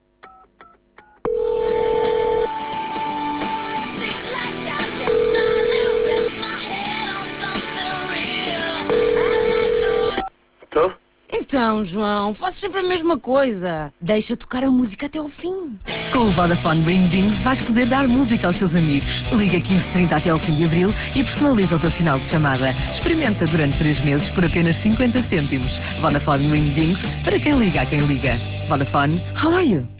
Dia 17 de Fevereiro estreou uma campanha de um novo serviço da Vodafone que personaliza o sinal de chamada - Ringdings - com 2 spots diferentes (
Em rádio esta campanha passou na Cidade FM, RFM, RC e Mega FM onde 315 inserções tiveram um investimento até ao dia 3 de Março de 99 244 euros, a preço tabela.